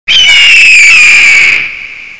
eagle_cry_leise.wav